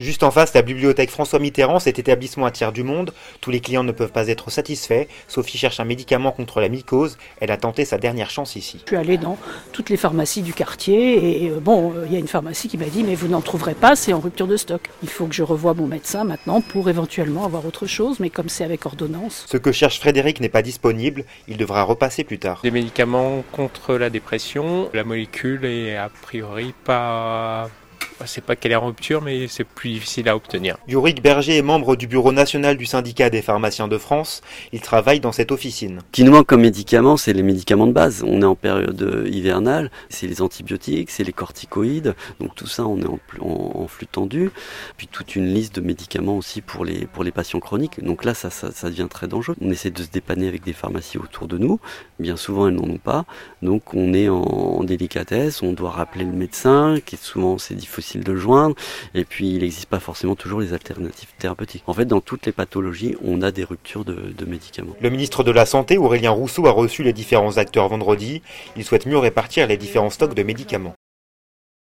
Reportage.